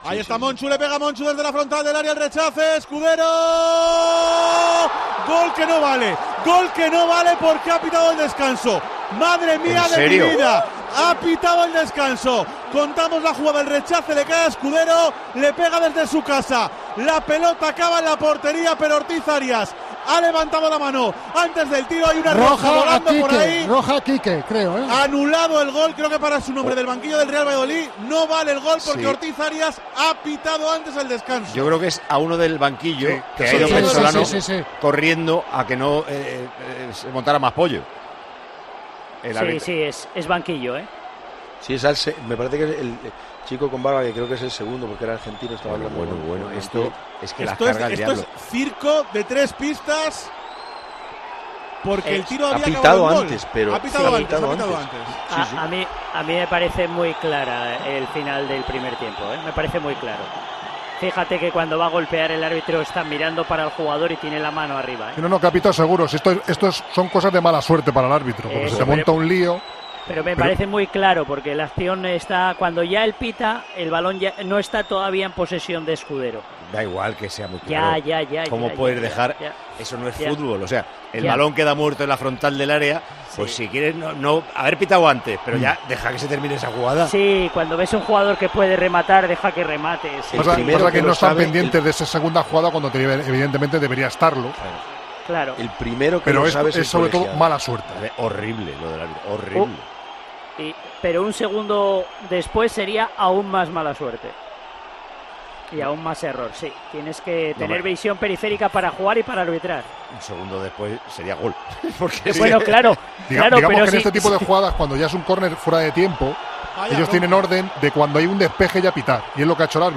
Paco González reacciona al polémico gol no válido al Valladolid: "Si esto pasa en un Madrid-Barcelona..."
Esta polémica fue analizada en Tiempo de Juego y el director y presentador del programa líder de la radio deportiva, Paco González, se pregunta qué hubiera pasado si esta jugada hubiera ocurrida en la última jornada con el el equipo pucelano jugándose el descenso o incluso se llega a preguntas qué hubiéra pasado en un Real Madrid-Barcelona.